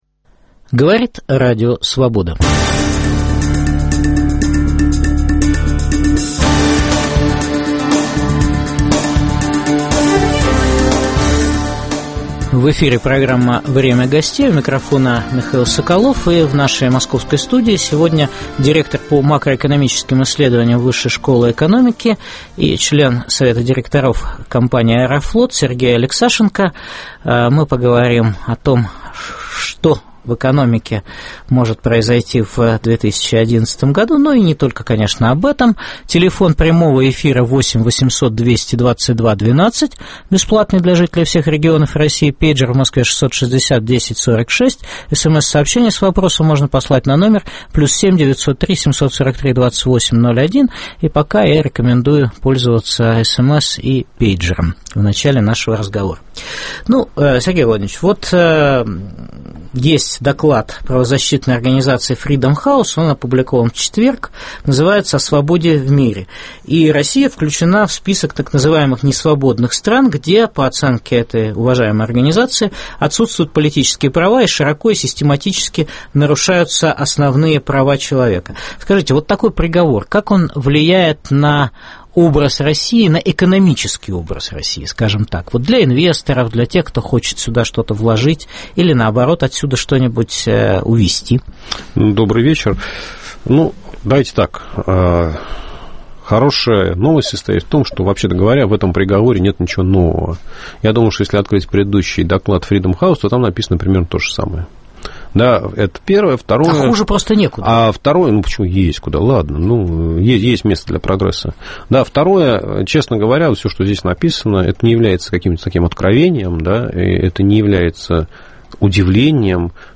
Что принесет России предвыборная экономическая политика правительства Владимира Путина? В программе выступит директор по макроэкономическим исследованиям Высшей школы экономики Сергей Алексашенко.